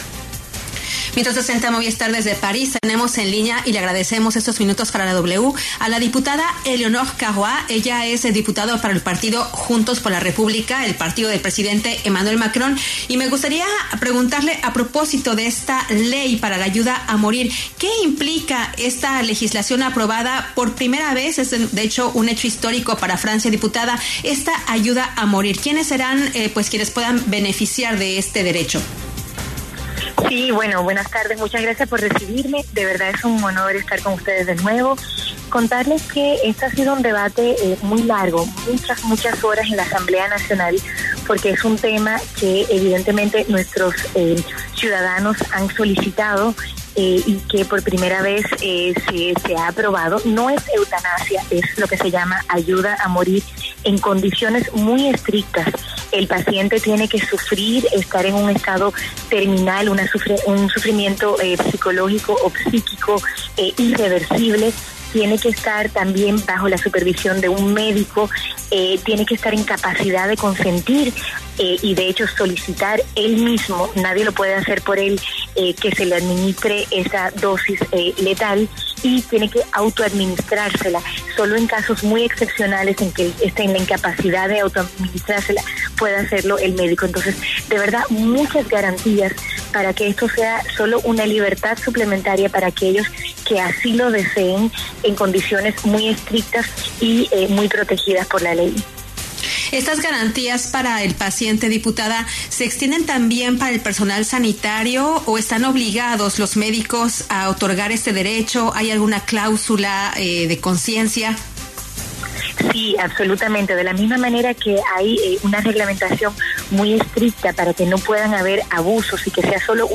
Eleonore Caroit, diputada francesa por el partido ‘Juntos por la República’ (Partido del presidente Macron), habló en La W sobre la ley ‘Ayuda para morir’ aprobada, por primera vez, en Francia.